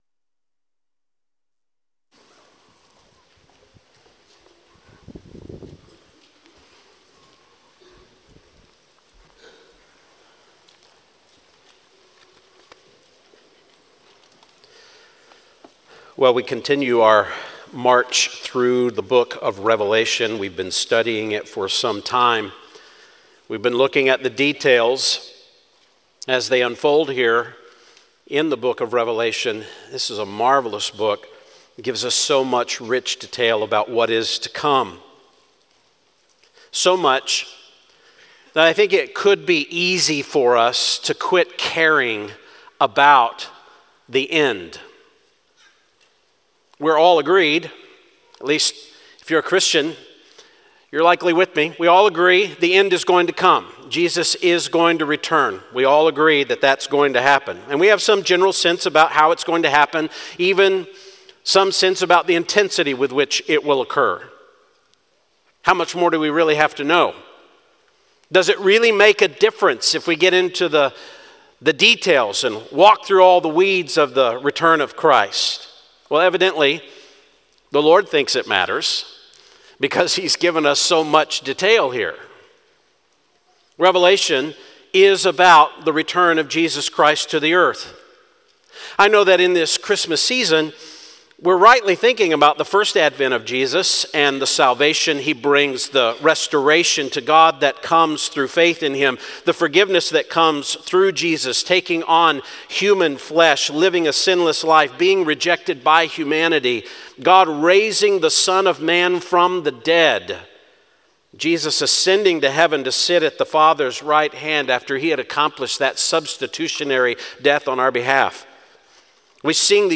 sunday-morning-12-8-24.mp3